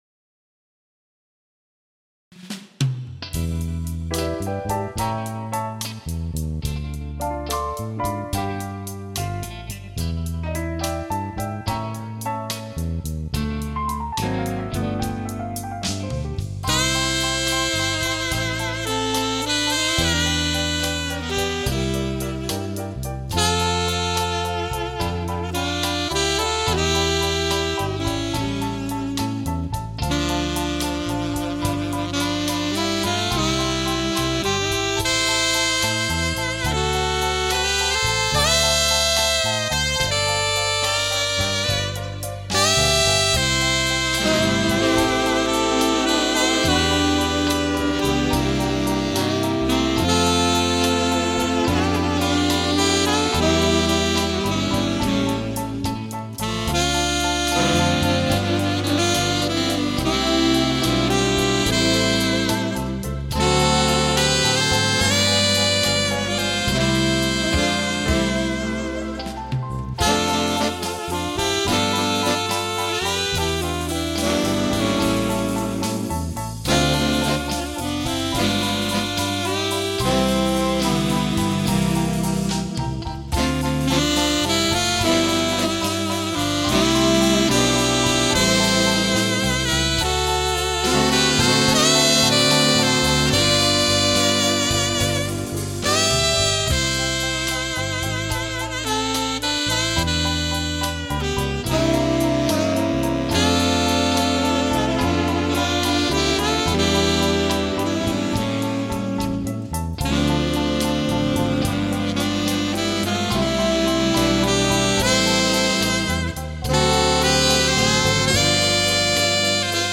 Multi-tracking